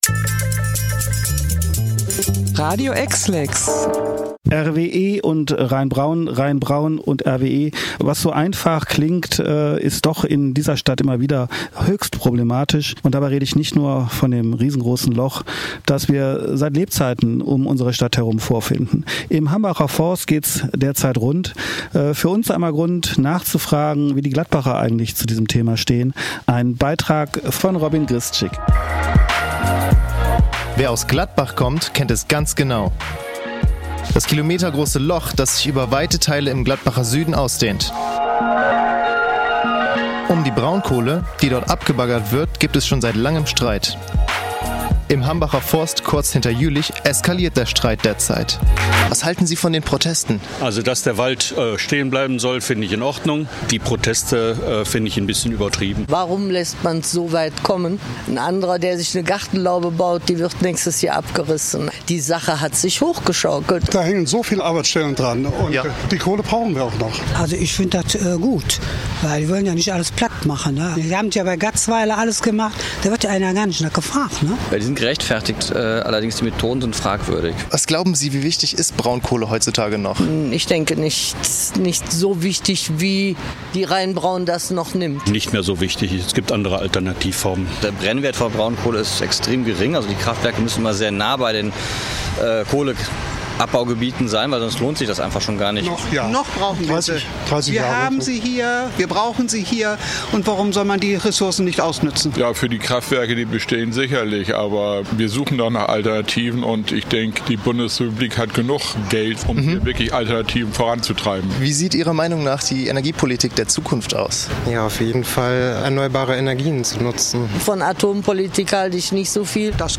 Hambacher Forst: Überzogene Proteste? Umfrage zur Braunkohlenutzung